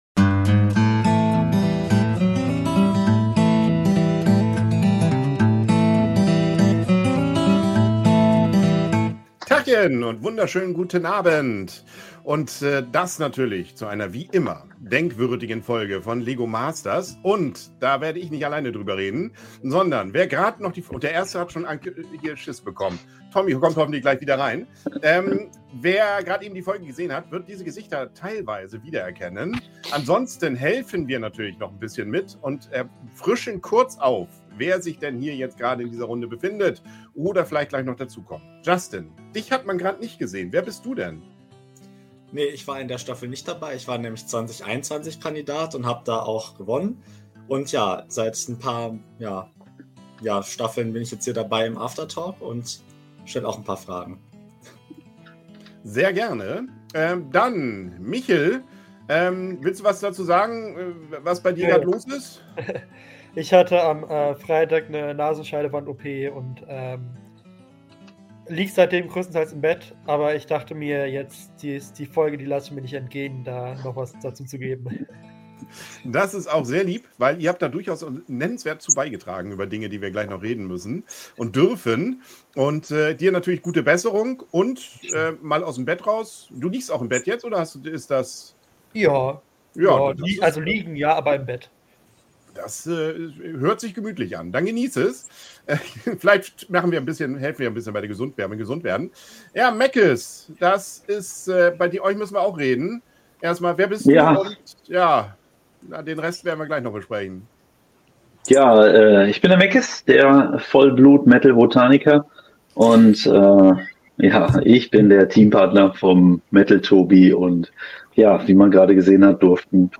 1 Live: LEGO Masters After-Talk 2025 mit Kandidaten (Folge 2) 1:23:34